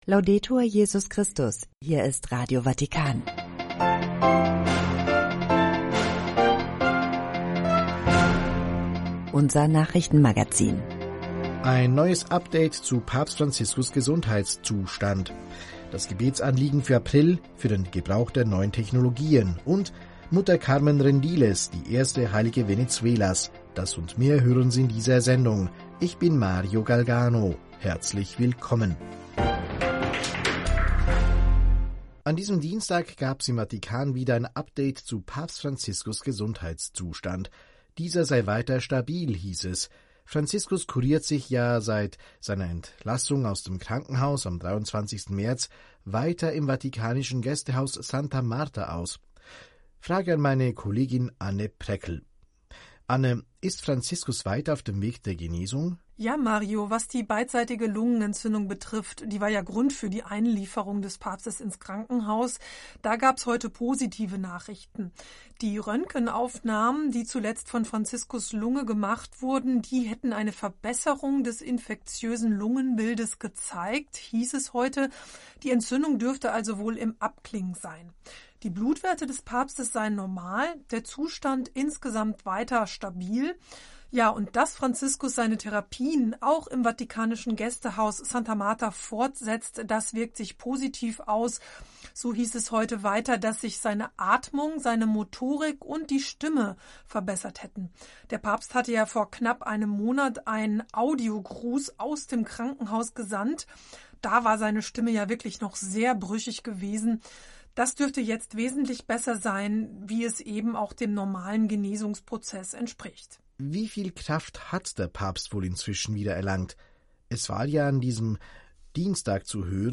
… continue reading 4 episódios # vatican city # Nachrichten # Radio Vaticana Vatican News